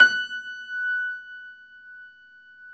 53d-pno20-F4.wav